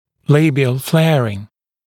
[‘leɪbɪəl ‘fleərɪŋ][‘лэйбиэл ‘флэарин]значительный вестибулярный наклон зубов (как правило, фронтальных)